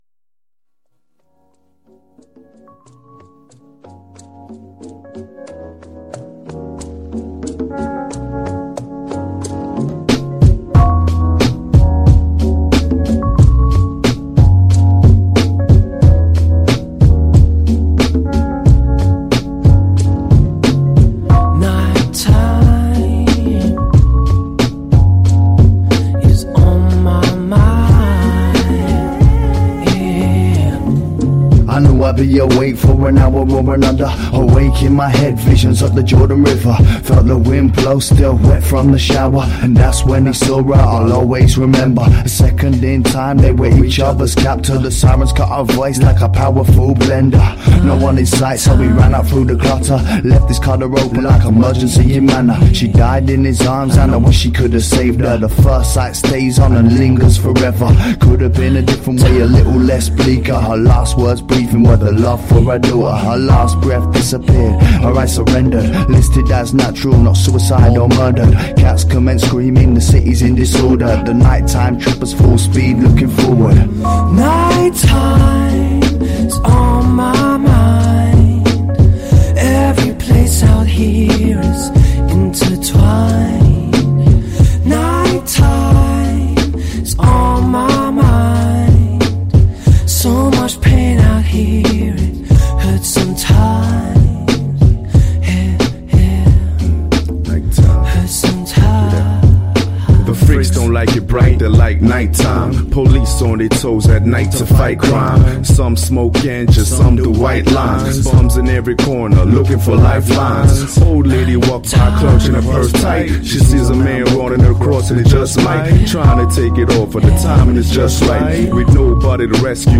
Rap track